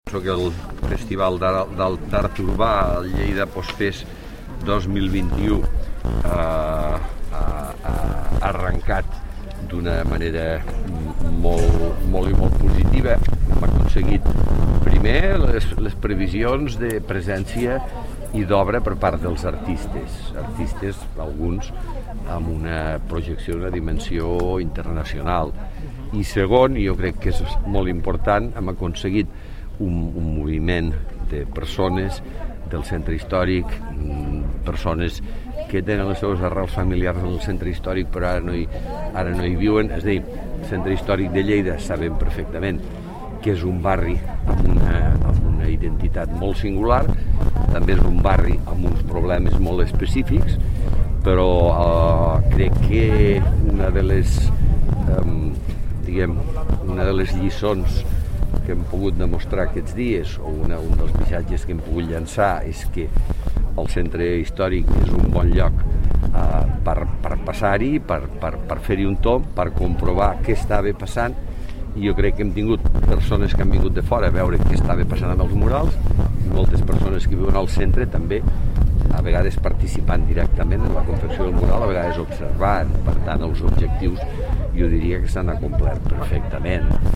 Tall de veu M. Pueyo
tall-de-veu-miquel-pueyo